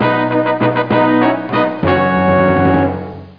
1 channel
FANFARE.mp3